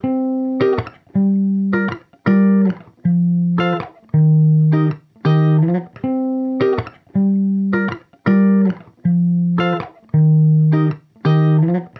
描述：爵士清唱吉他
Tag: 80 bpm Hip Hop Loops Guitar Electric Loops 2.02 MB wav Key : Unknown Ableton Live